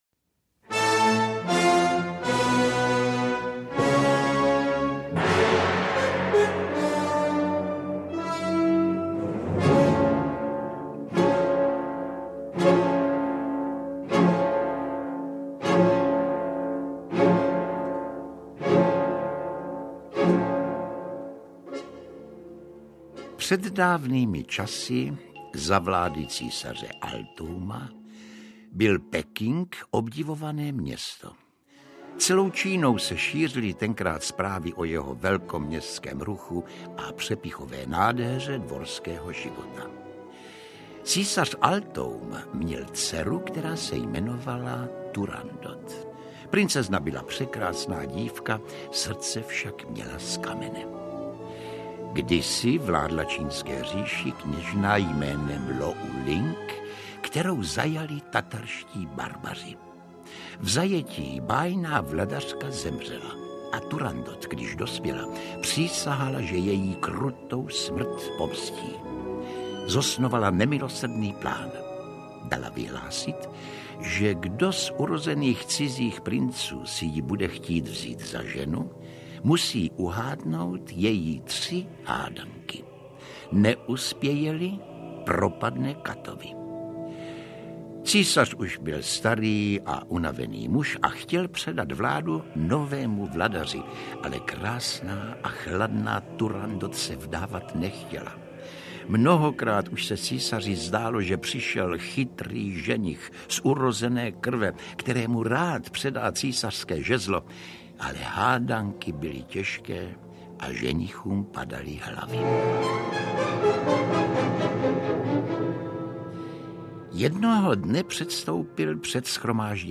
Audio knihaNebojte se klasiky 16 - Turandot